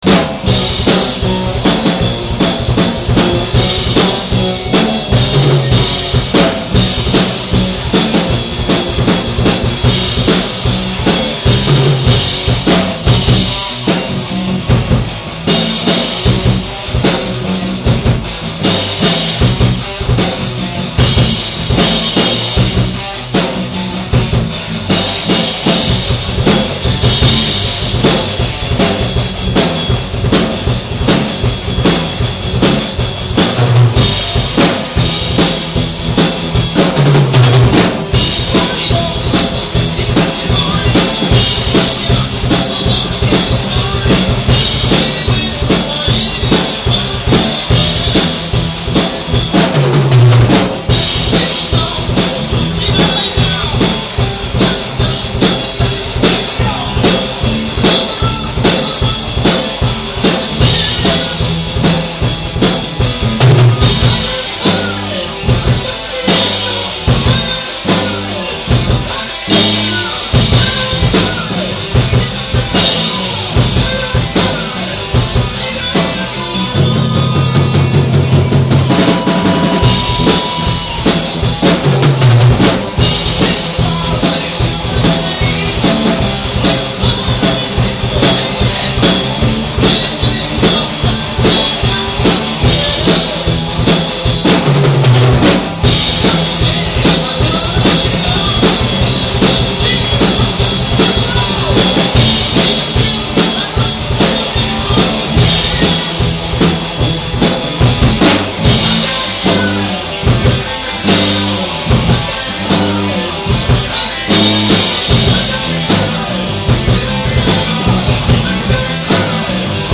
Запись с репетиций